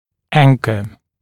[‘æŋkə][‘энкэ]фиксатор, опорный элемент; закреплять, фиксировать; якорь